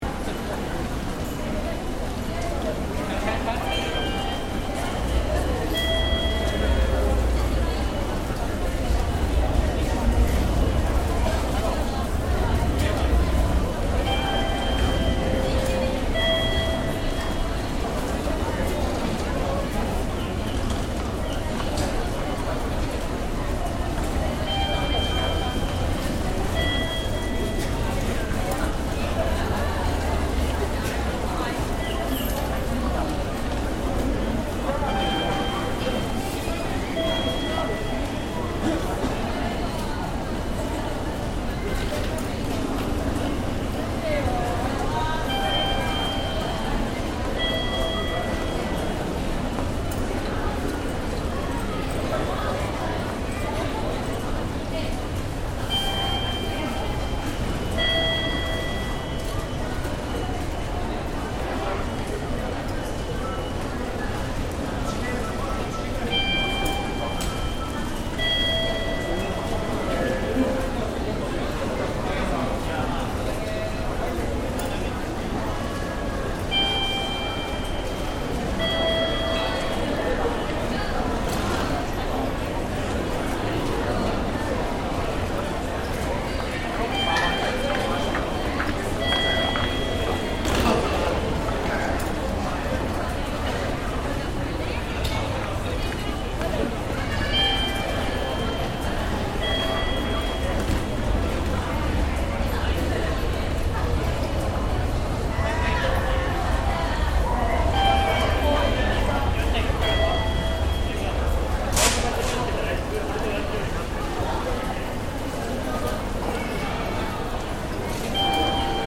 Inside Akihabara Station, Tokyo
Part of the Until We Travel project to map and reimagine the sounds of transport and travel in a pre-pandemic and pandemic world.